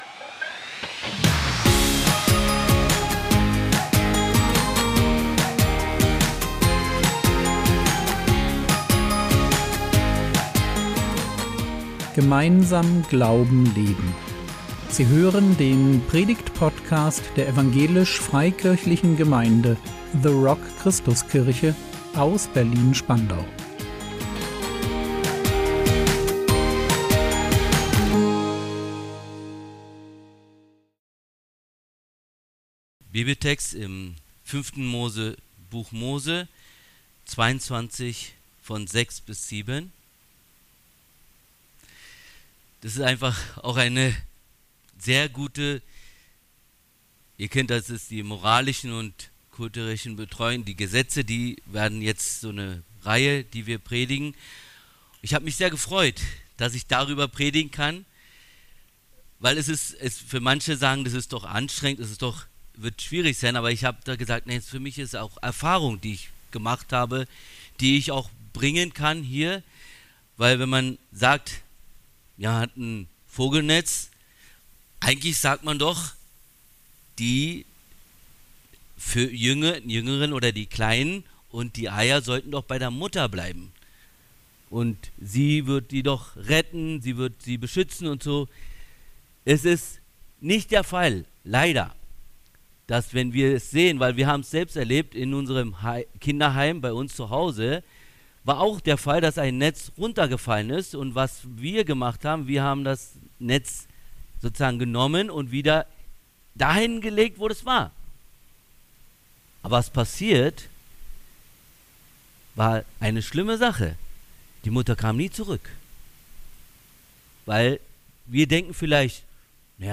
Ein Herz für das Nest | 19.10.2025 ~ Predigt Podcast der EFG The Rock Christuskirche Berlin Podcast